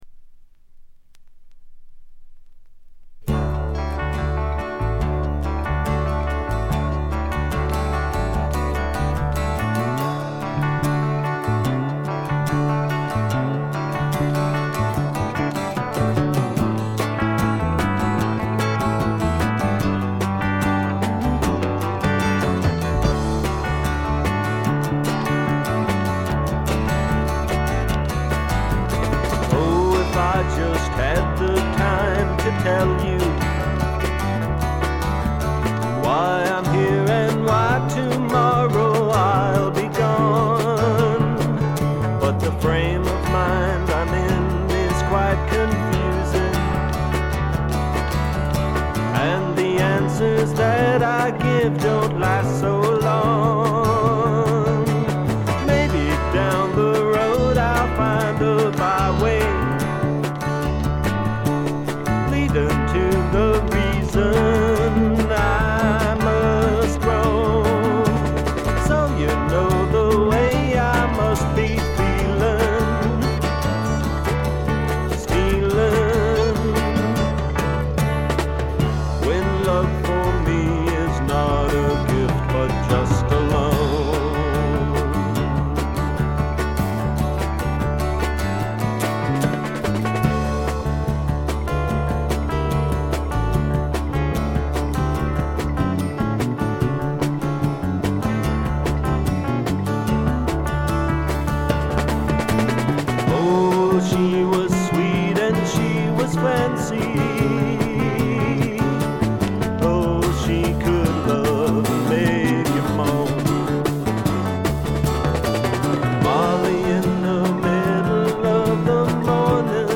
これ以外は静音部で少しチリプチが聴かれる程度。
試聴曲は現品からの取り込み音源です。